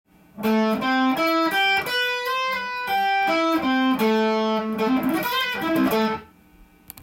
tab譜のフレーズはAmキーで使用できます。
オシャレ系スィープフレーズです。
Am９のコードトーンを弾いているのでAm上で
スムーズジャズっぽく聞こえるカッコよさ。